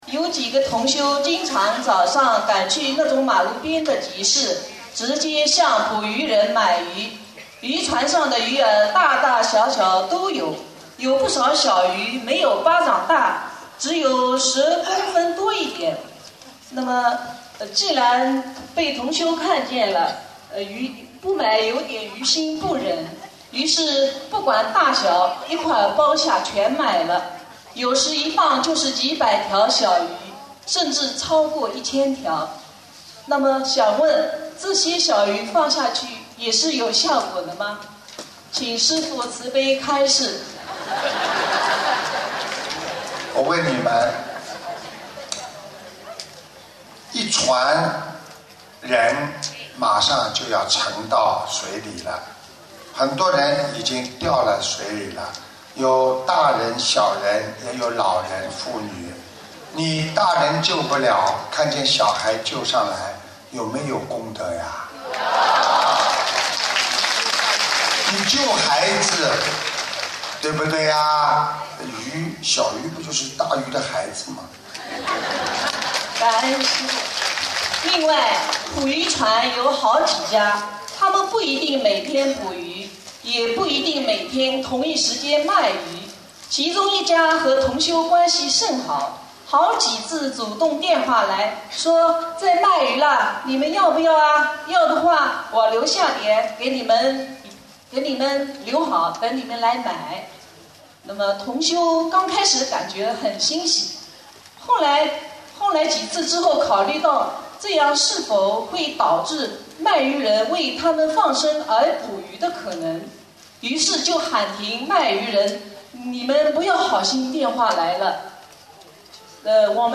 首页 >>博客精选 >> 2015年弟子提问师父答